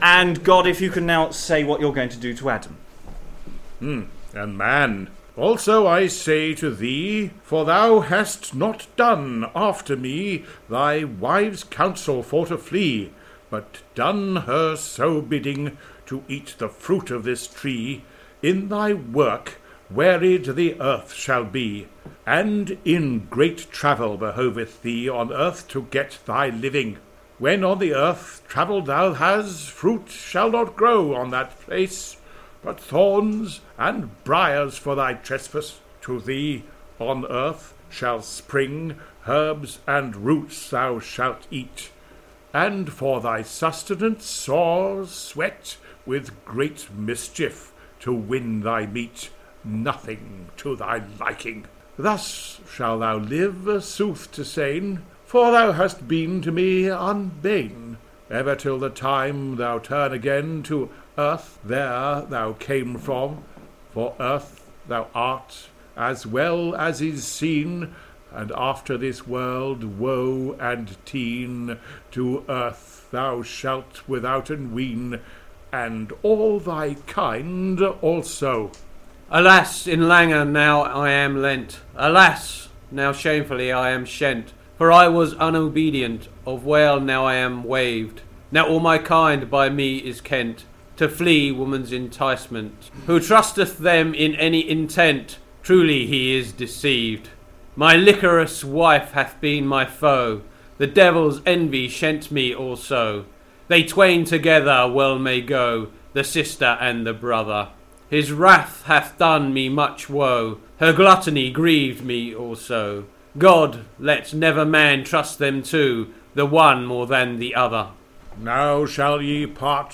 Facebook Twitter Headliner Embed Embed Code See more options Third part of the second pageant of the Chester cycle - featuring the end of the fall of Adam and Eve and the introduction of the Cain and Abel story. Exploring the Chester Mystery Plays is a series of live streamed events where the Chester plays are taken apart with readers and commentary. Rough round the edges, these edited versions of these events are now being posted online.